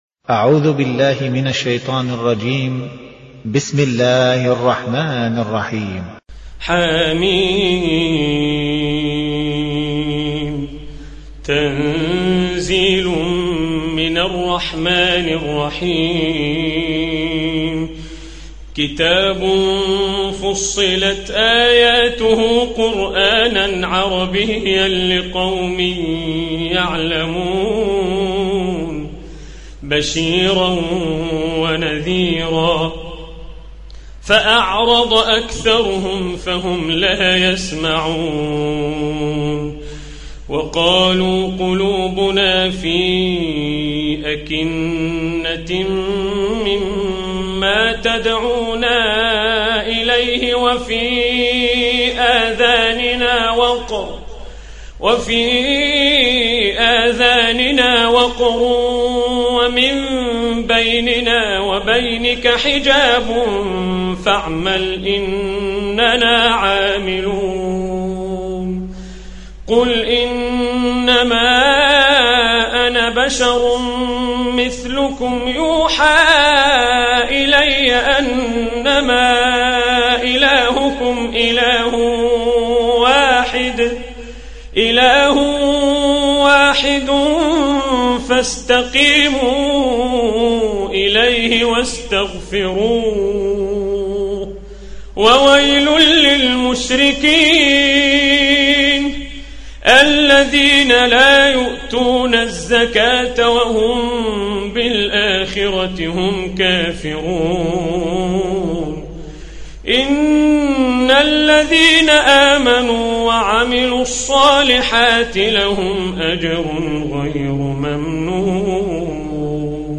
41. Surah Fussilat سورة فصّلت Audio Quran Tarteel Recitation
Surah Repeating تكرار السورة Download Surah حمّل السورة Reciting Murattalah Audio for 41.